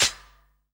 D2 SHAK-80.wav